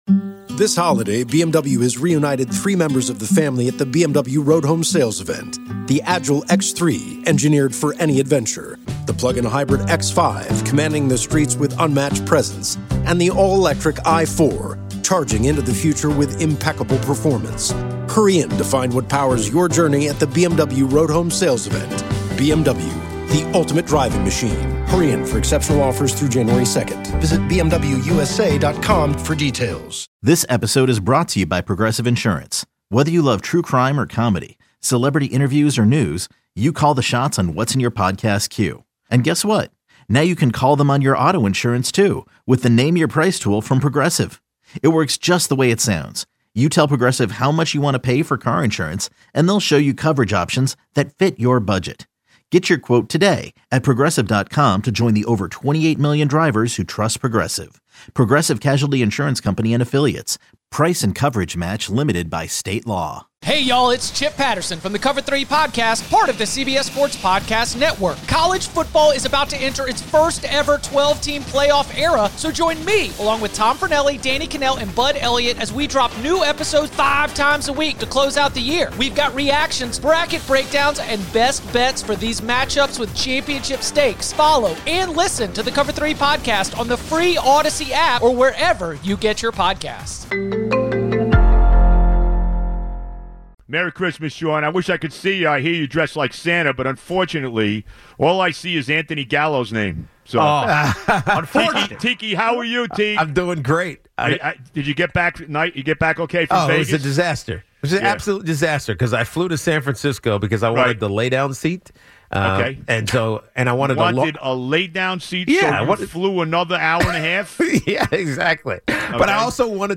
Boomer Esiason and Gregg Giannotti talk sports and interview the hottest names in sports and entertainment.